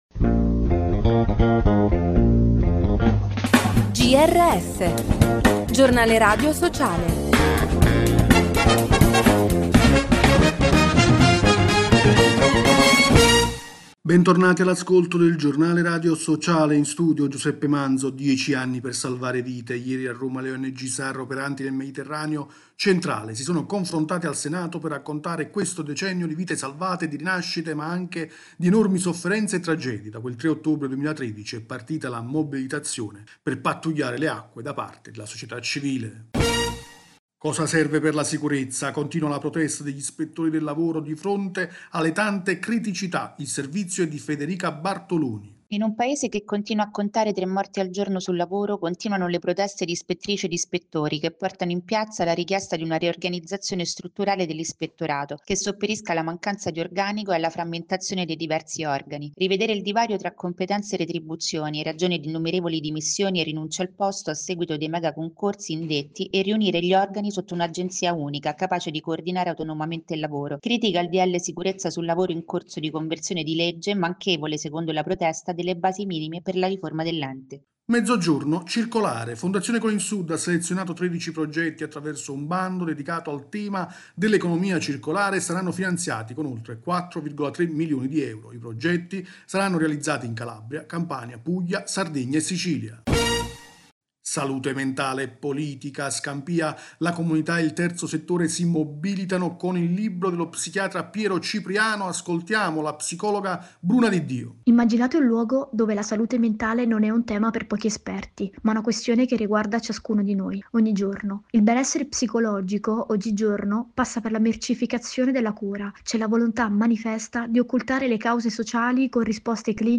Bentornati all’ascolto del Giornale radio sociale.